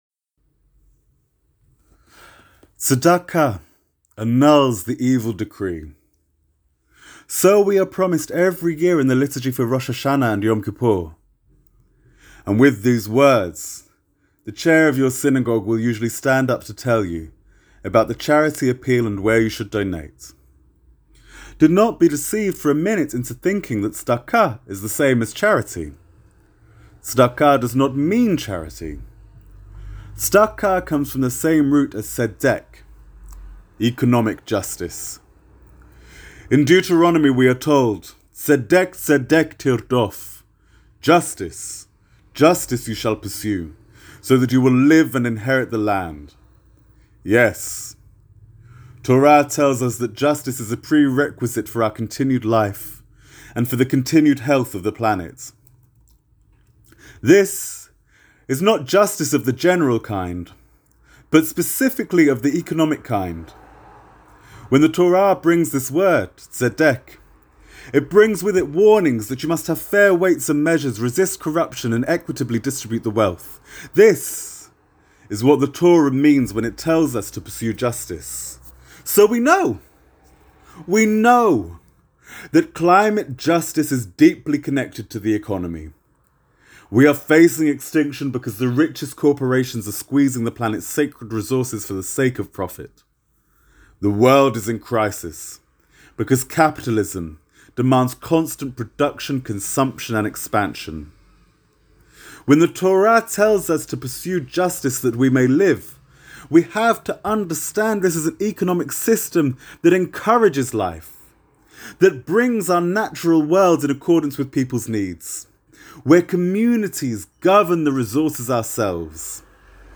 Tonight I will attend a protest against climate change in Parliament Square with Extinction Rebellion Jews. My speech for the demonstration is below.